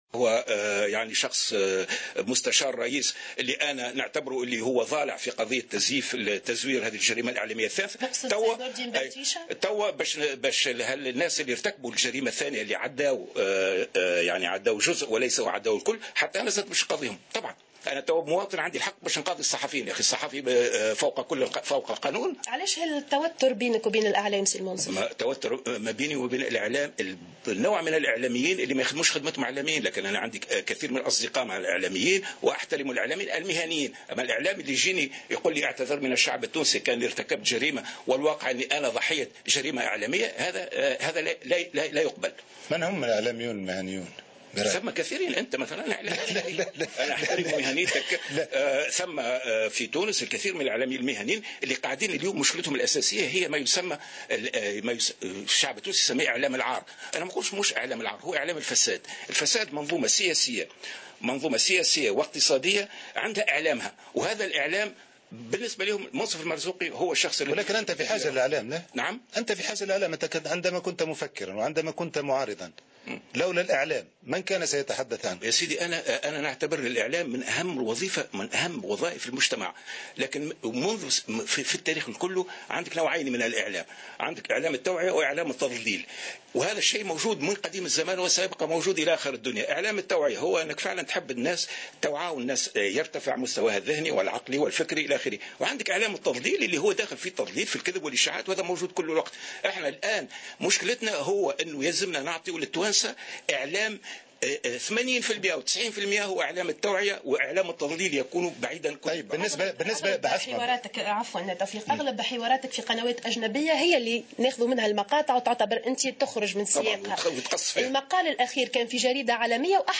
واعتبر المرزوقي، في حوار مع قناتي فرانس 24 والوطنية الأولى، اليوم الجمعة، أنه كان ضحية لجريمة إعلامية، واصفا بعض وسائل الإعلام التونسية بـ"إعلام الفساد" الخاضع لمنظومة سياسية فاسدة، حسب تعبيره.